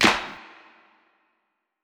TC2 Clap1.wav